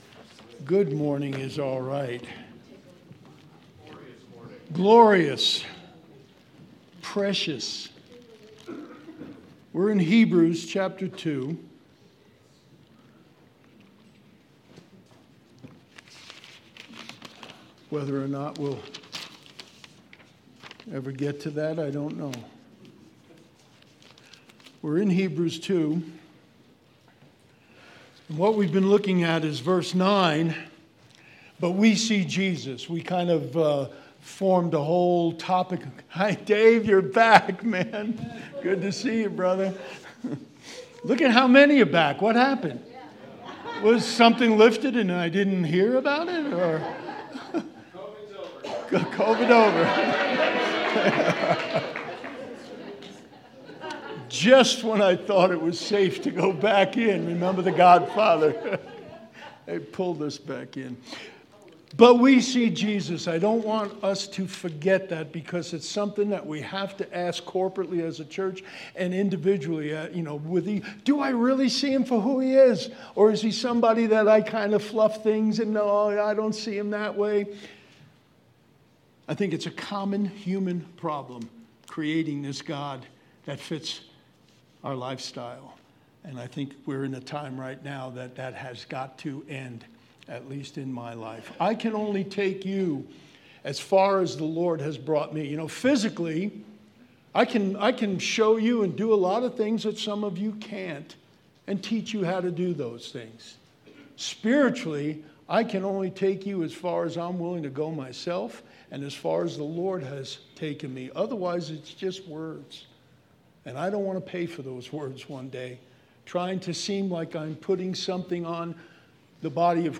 February 6th, 2022 Sermon